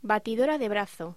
Locución: Batidora de brazo